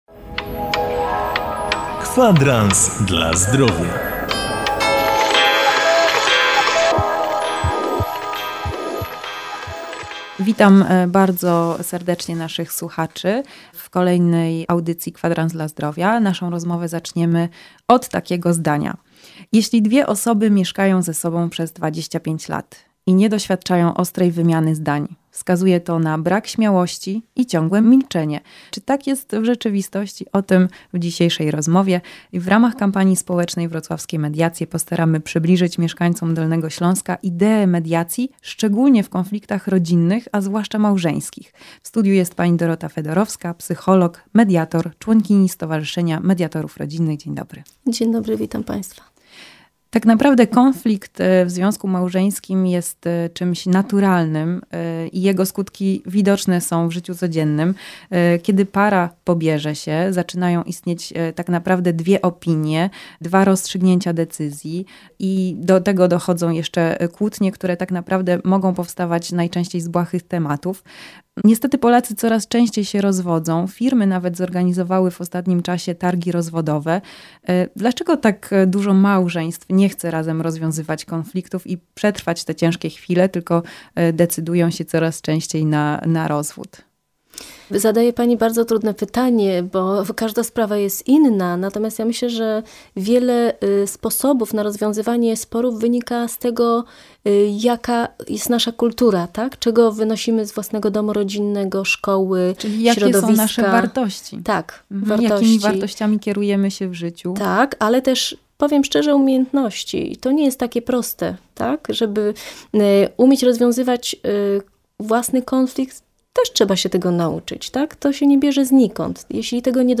CZYM JEST MEDIACJA RODZINNA ? Audycja radiowa umożliwia uzyskanie odpowiedzi na najczęściej zadawane pytania dotyczące mediacji w sprawach rodzinnych.